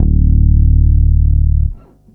4-D1.wav